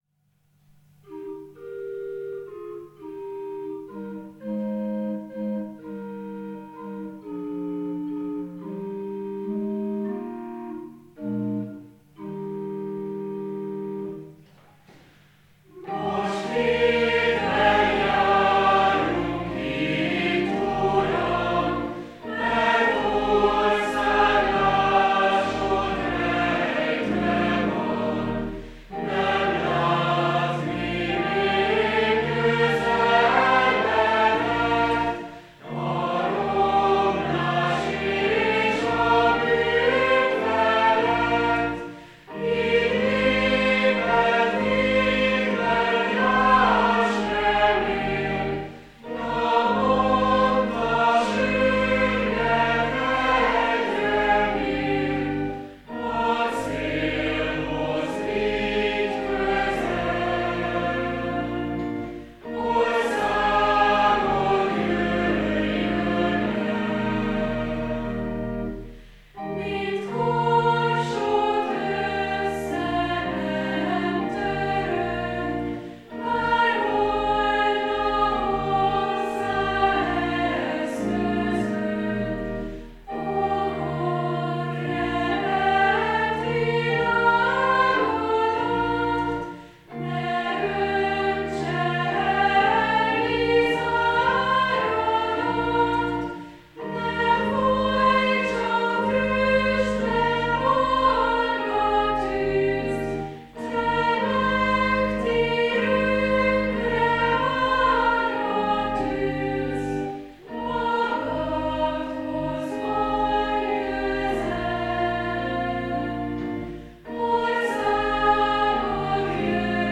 A Jer, tárjunk ajtót még ma mind (EÉ 137) karakteres adventi ének, így nagyszerűen illik Jézus második eljövetelének várásához.